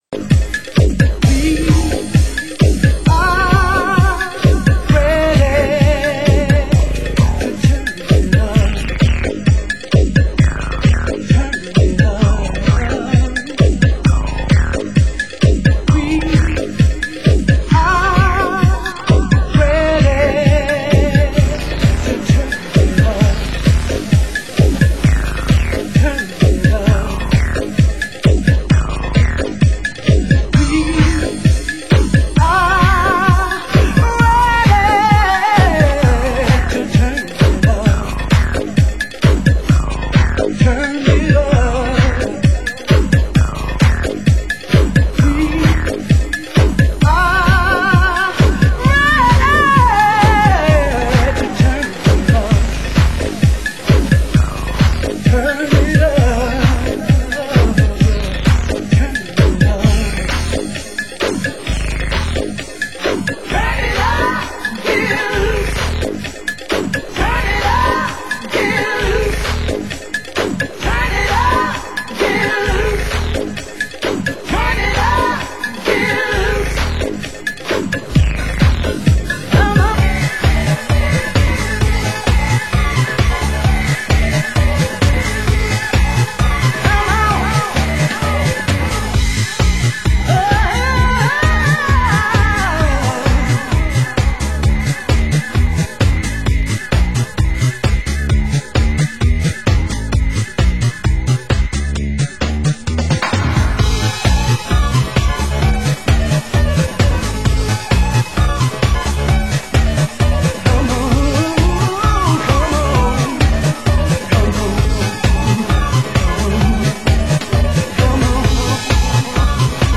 Genre US House